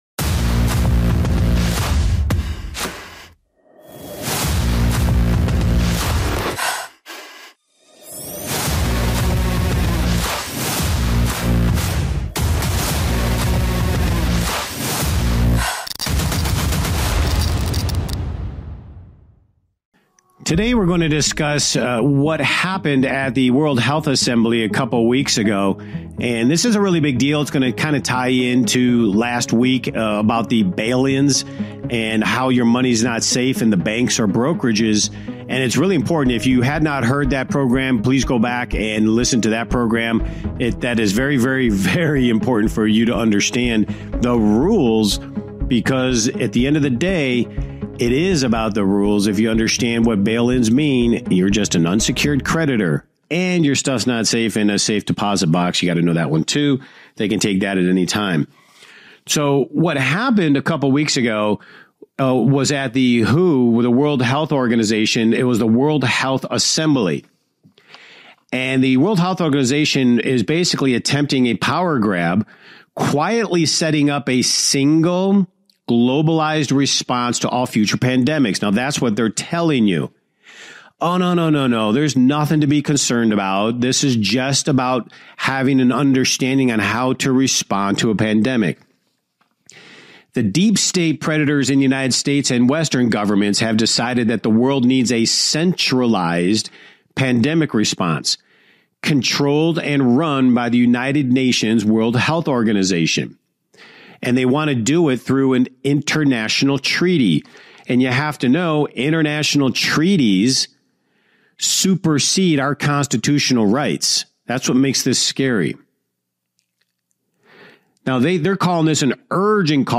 Talk Show Episode, Audio Podcast, Rigged Against You and By 2025 50-percent Of People Will Have This Implanted on , show guests , about By 2025,50-percent Of People,Will Have This Implanted, categorized as Business,Investing and Finance,History,News,Politics & Government,Society and Culture,Technology